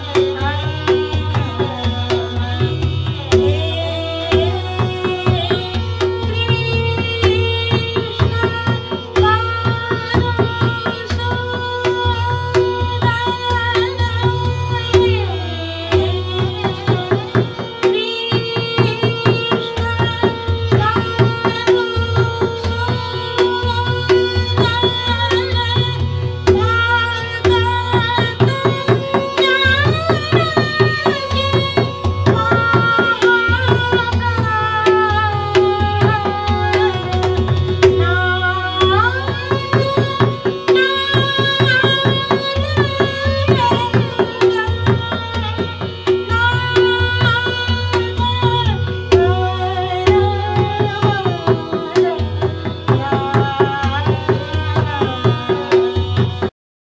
Musically, the abhog typically encompasses the melodic range from the middle notes of the middle octave (madhya saptak) to those of the upper octave (taar saptak).